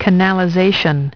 Transcription and pronunciation of the word "canalization" in British and American variants.